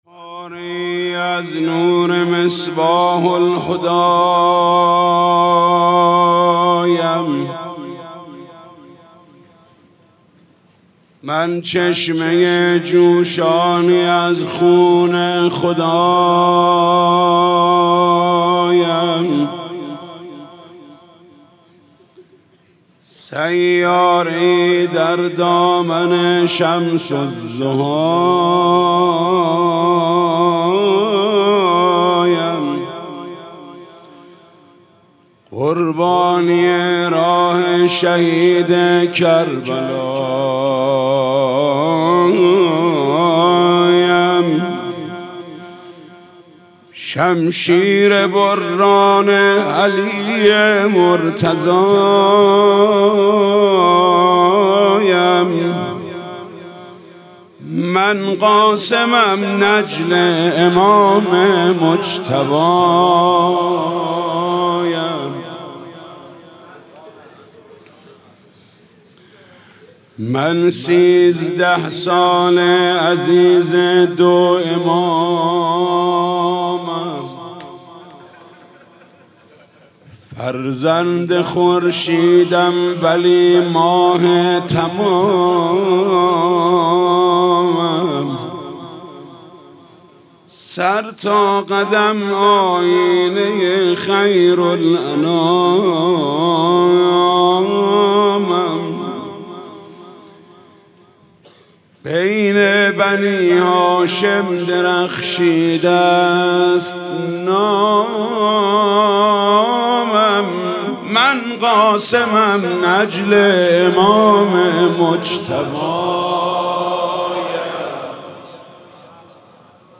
مناسبت : شب ششم محرم
مداح : محمود کریمی